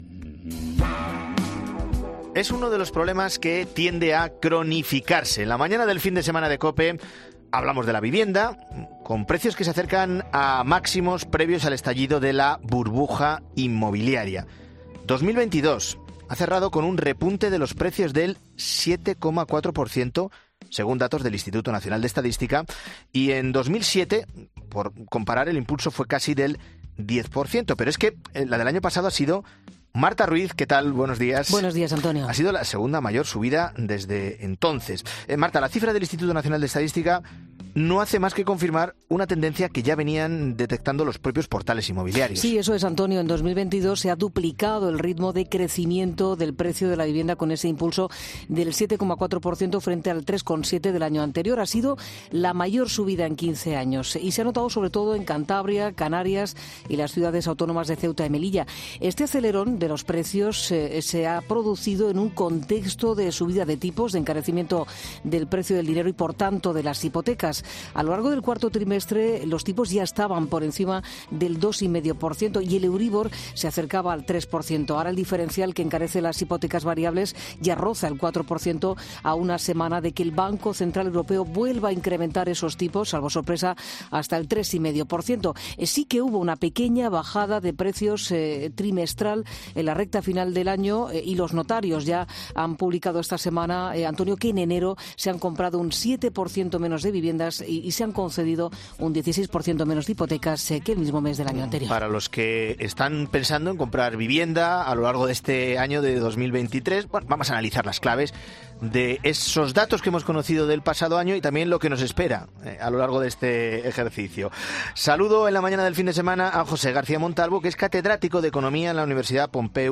analizan el mercado del alquiler en España, en La Mañana Fin de Semana